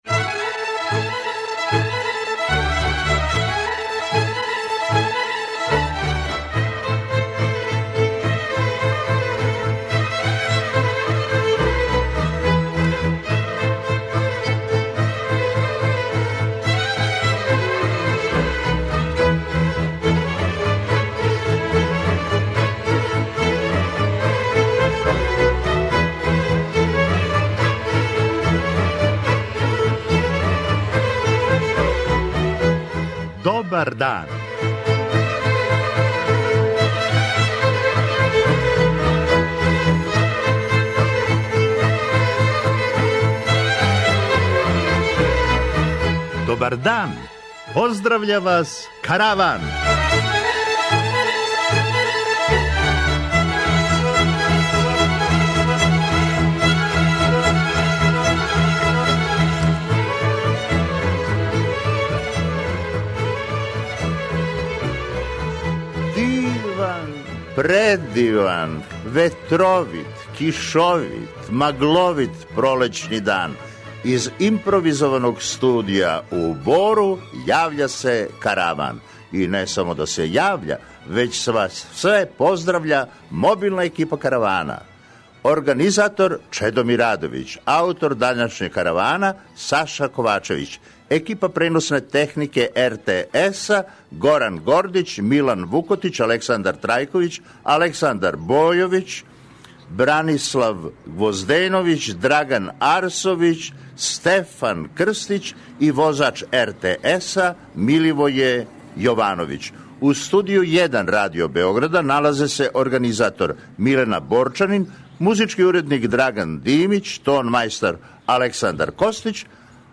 Данас се јављамо из регије зване Тимочка Крајина.
Да ли сте погодили где смо - у Бору!
Будите уз нас, поред сталних рубрика, данас имамо и госте - они нам стижу из Центра за развој Бора, као из амбасаде Шведске.